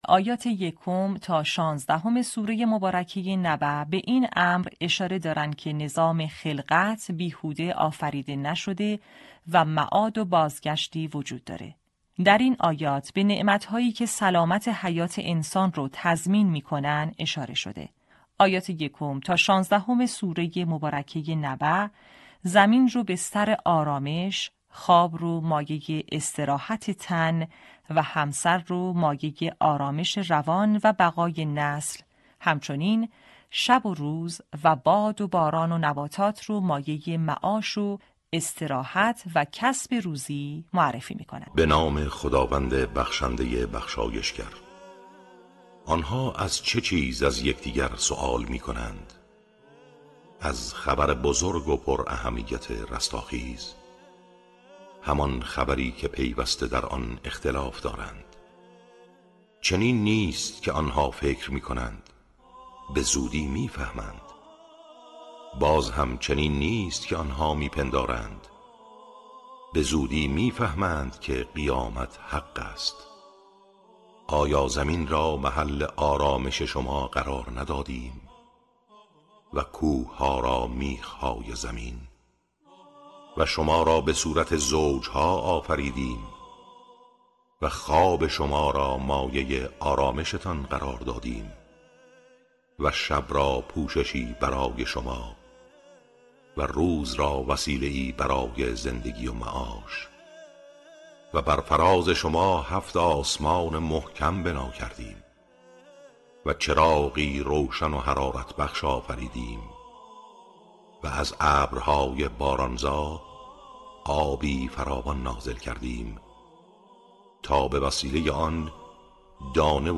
آموزش حفظ جزء 30 آیات 1 تا 16 سوره نبأ